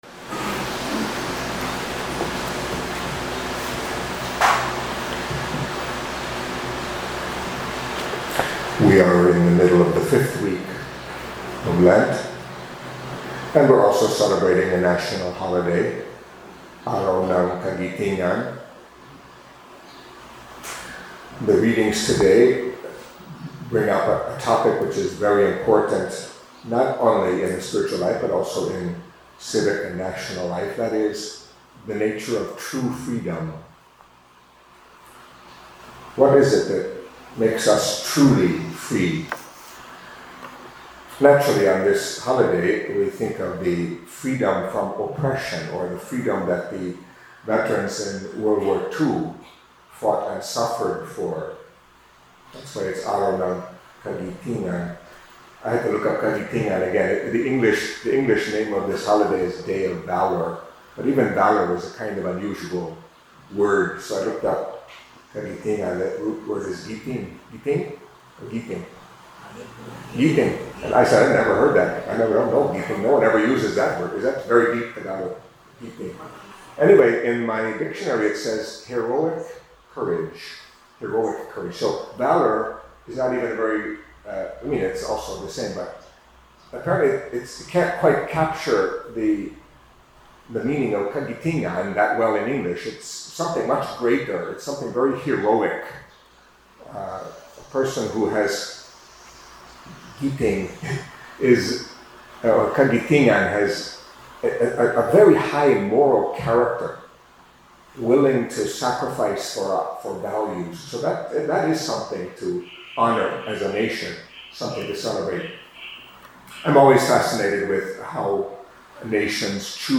Catholic Mass homily for Wednesday of the Fifth Week of Lent